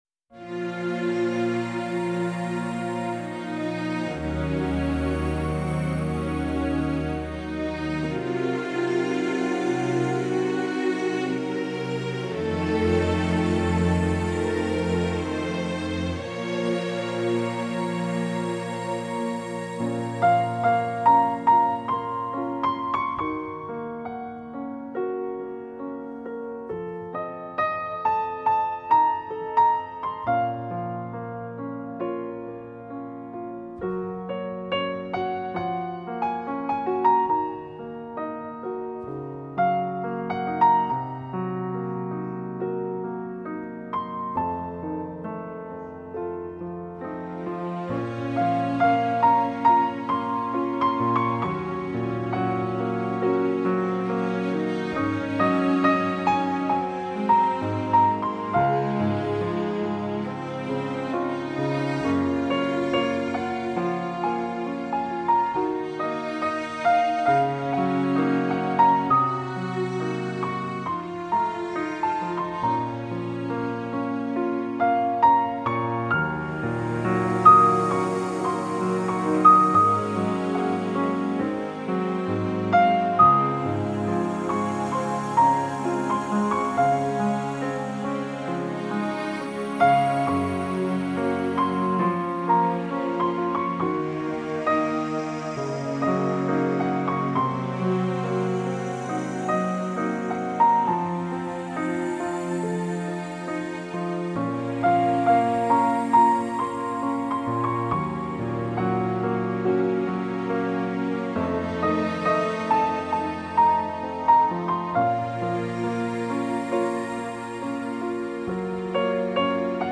Жанр: Relax